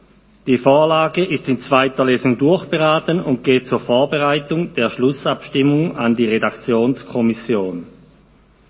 2.12.2020Wortmeldung
Session des Kantonsrates vom 30. November bis 2. Dezember 2020